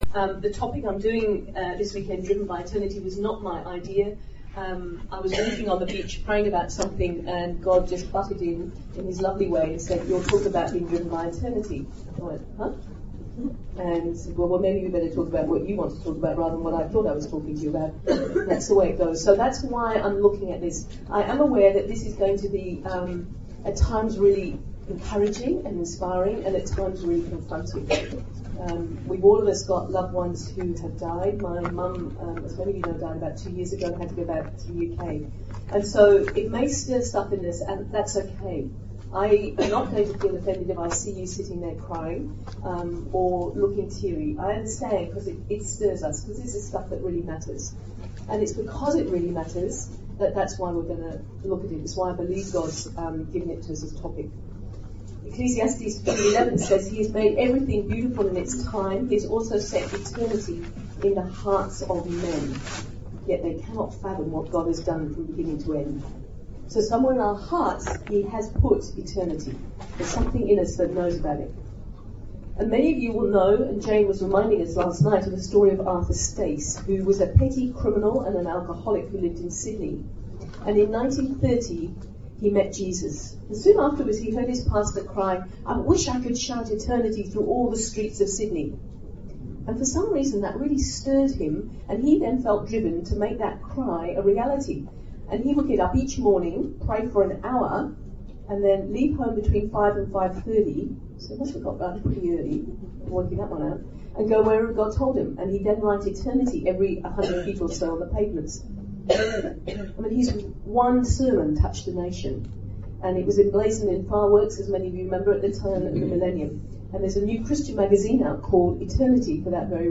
Current Sermon
Guest Speaker